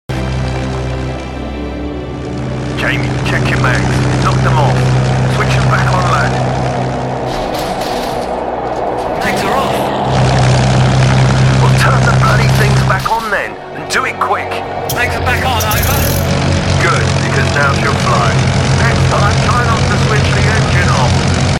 WW2 RAF SpitfireMk1s Part 2 - Engine Restart